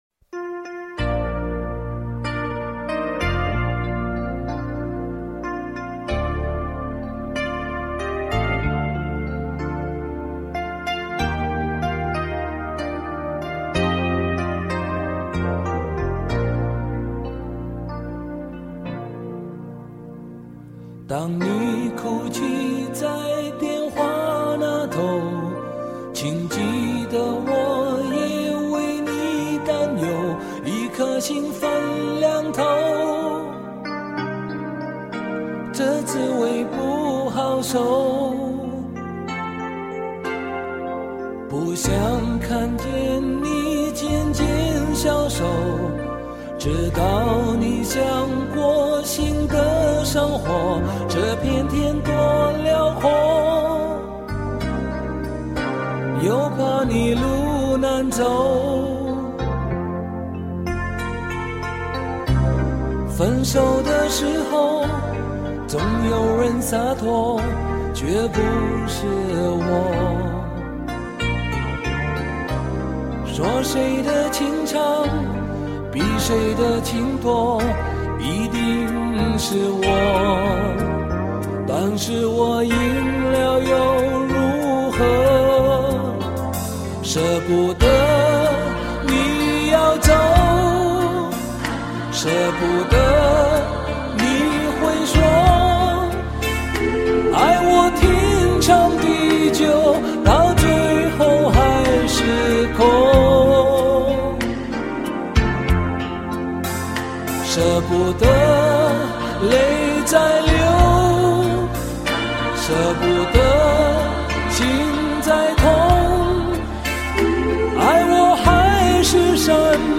而且他还是乐队的键盘，他的嗓音非常有磁性，显得很沧桑。